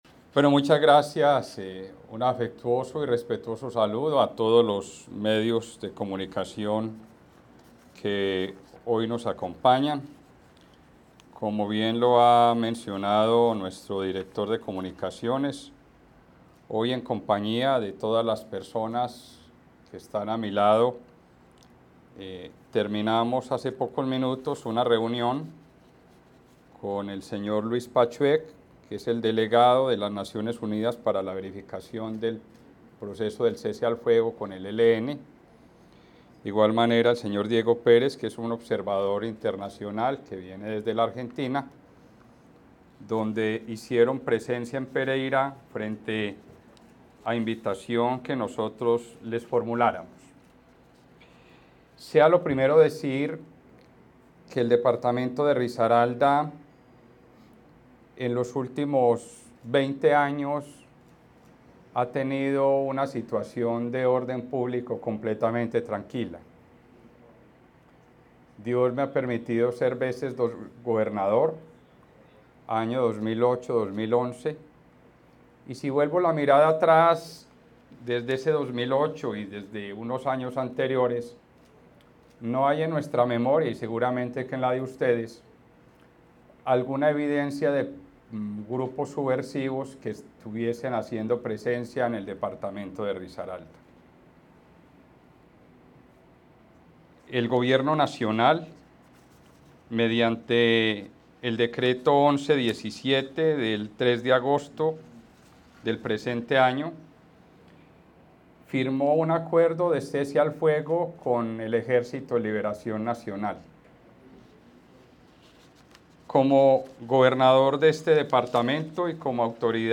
Pereira, septiembre 11 de 2023 En rueda de prensa, y tras una reunión con delegados de la comisión de verificación de la ONU y observadores internacionales, las autoridades civiles, militares, de policía y eclesiásticas en cabeza del gobernador de Risaralda, mostraron su preocupación tras constatarse presencia del ELN, Frente Manuel Hernández El Volche en el municipio de Mistrató, donde se ha registrado coacción a la población civil de la zona rural y semiurbana.
RUEDA-DE-PRENSA-GOBERNADOR-AUDIO.mp3